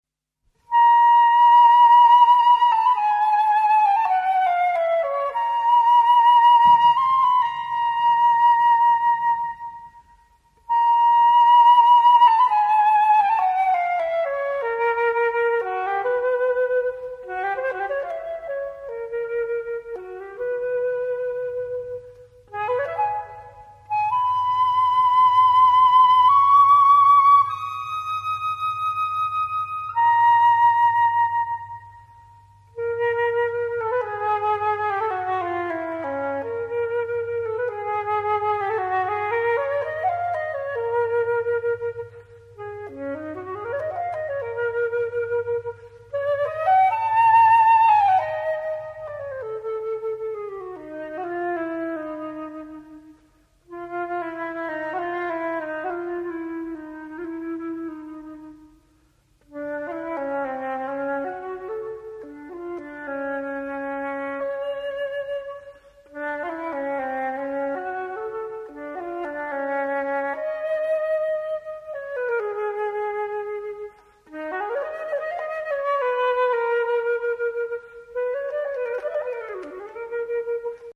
巴哈、CPE巴哈、德布西、奧乃格、泰雷曼／長笛作品